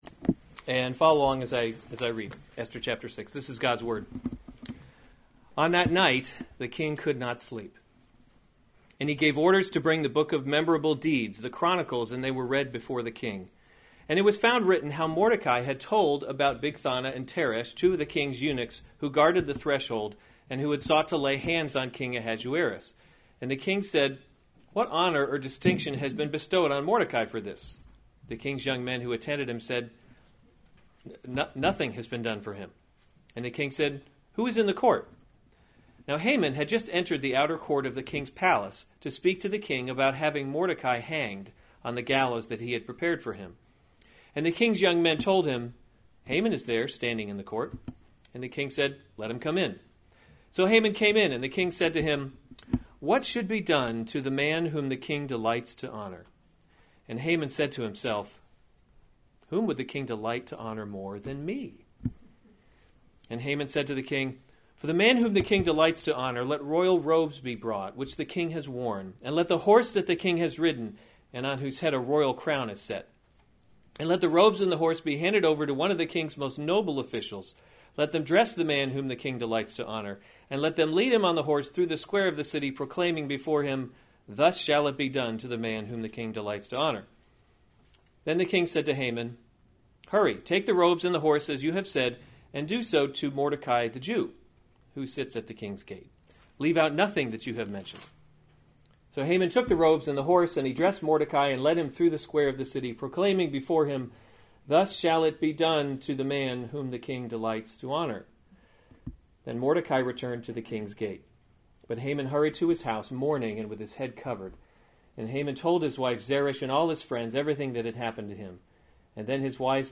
November 5, 2016 Esther: God’s Invisible Hand series Weekly Sunday Service Save/Download this sermon Esther 6:1-14 Other sermons from Esther The King Honors Mordecai 6:1 On that night the king could […]